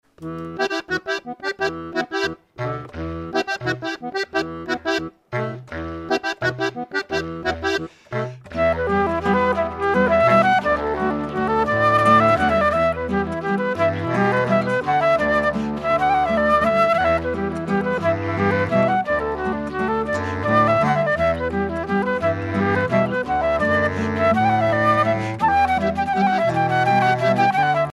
Valse
Informateur(s) Ellébore (association)
danse : valse
Concert donné en 2004
Pièce musicale inédite